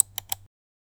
mouse click electric
mouse-click-electric-3vh3svfg.wav